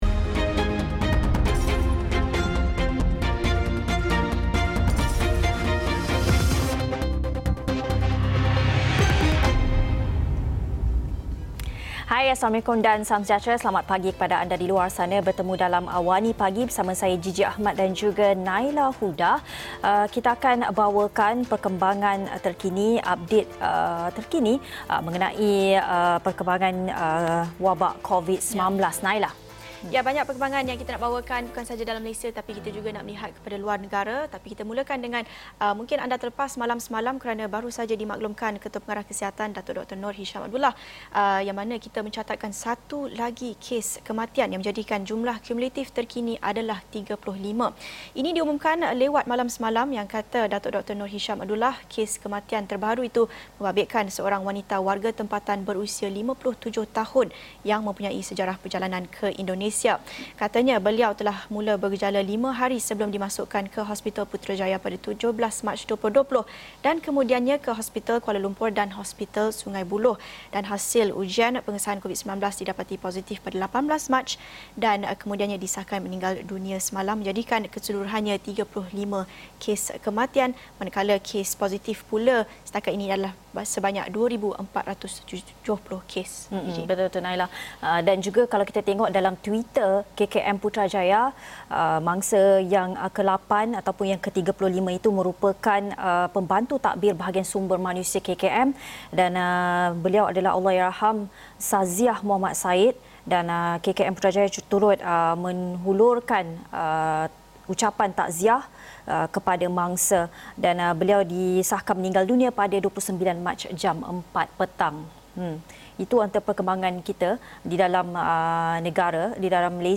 AWANI Pagi: Berita kemaskini COVID-19 [30 Mac 2020]